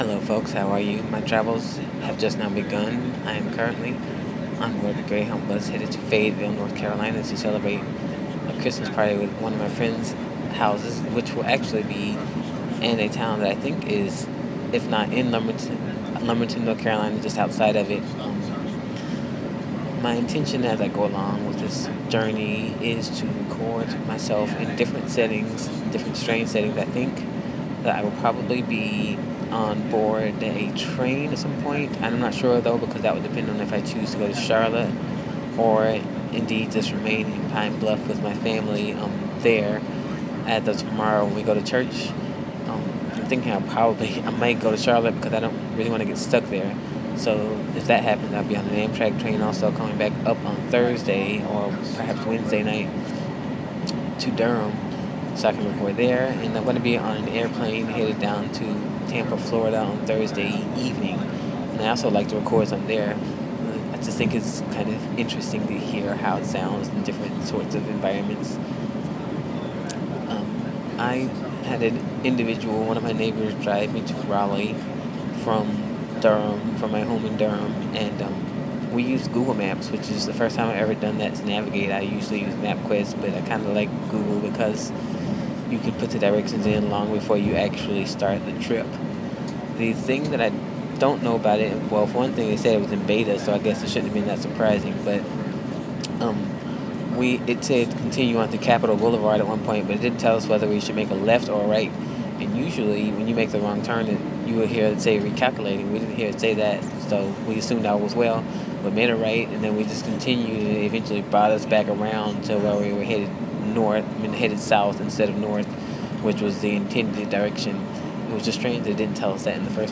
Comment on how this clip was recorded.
The Travel Begins: in The Front Seat of a Greyhound Bus